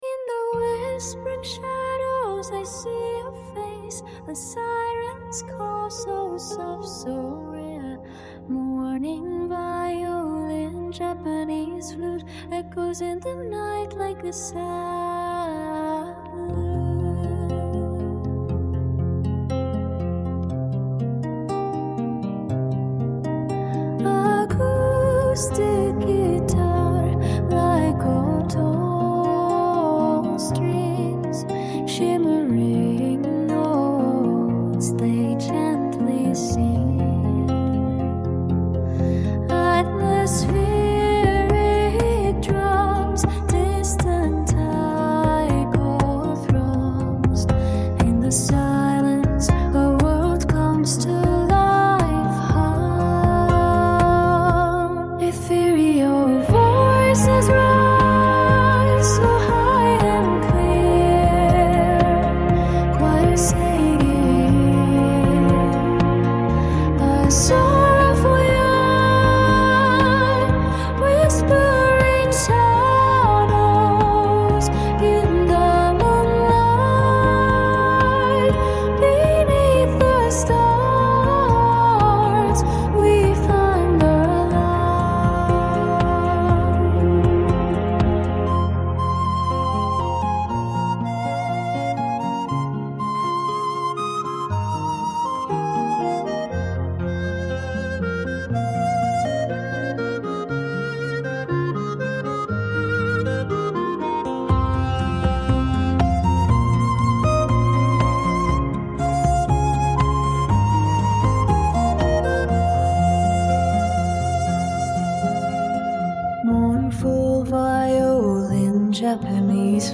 Irish music